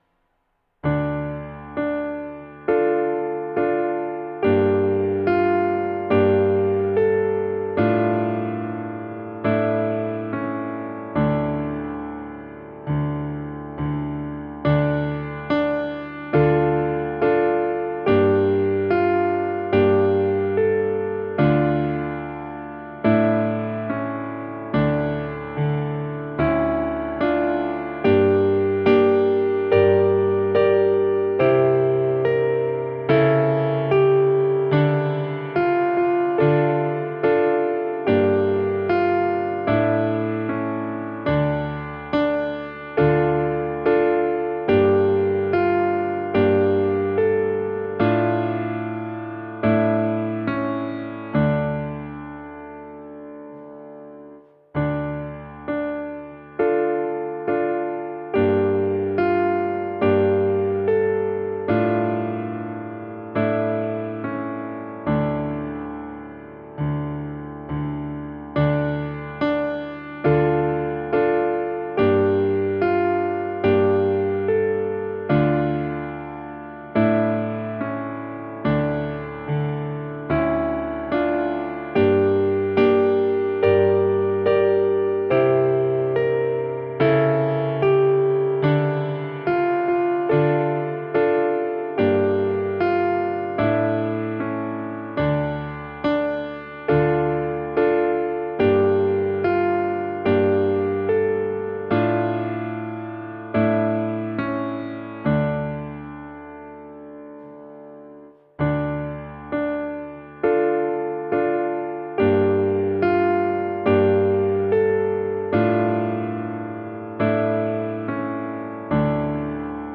– à 0:15 chanté 2,5 fois (il manque le premier A)